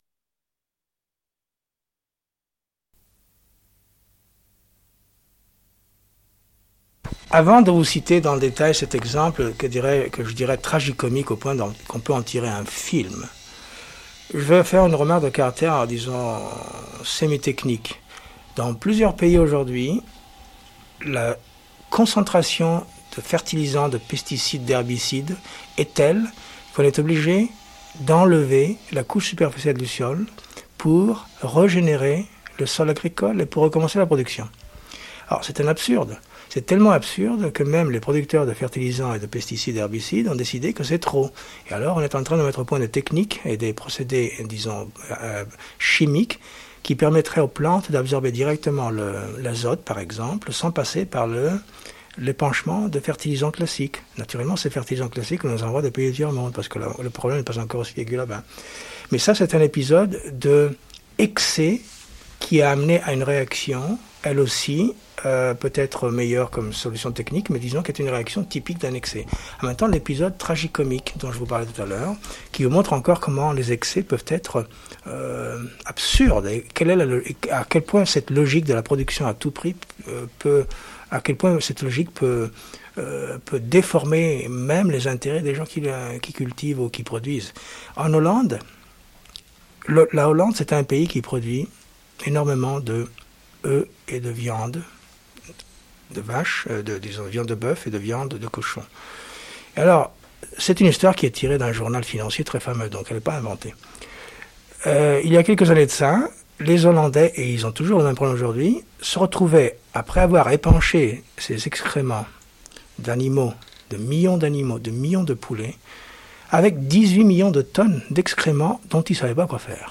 Une cassette audio